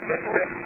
Class A EVP's